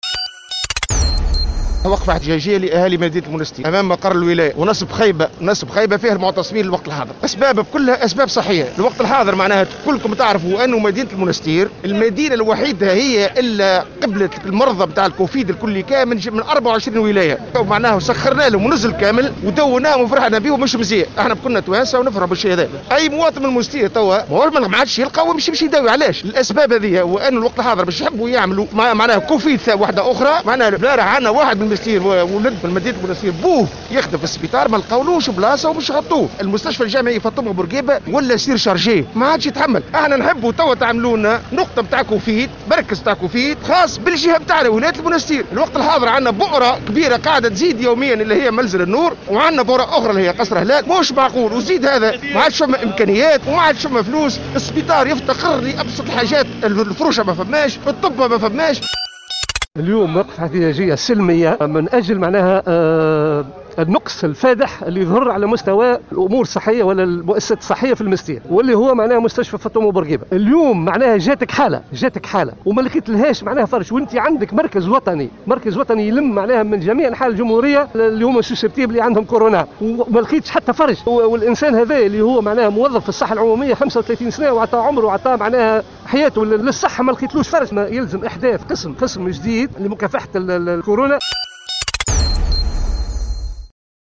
انتظمت اليوم السبت أمام مقر ولاية المنستير ، وقفة احتجاجية شارك فيها أهالي مدينة المنستير، على خلفية الوضع الصحي وتفشي وباء كورونا، في الولاية، مطالبين بتركيز وحدة كوفيد 19 خاصة بأهالي الجهة، وفق ما صرح به بعض المحتجين لمراسل الجوهرة أف أم.
تصريحات المحتجين تحميل المشاركة علي